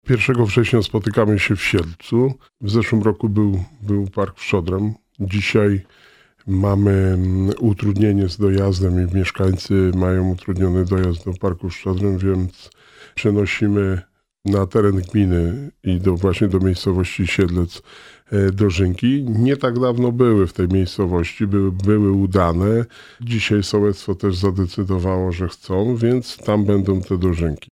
Mówi Wojciech Błoński, Wójt Gminy Długołęka.